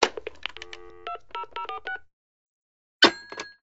SA_hangup.ogg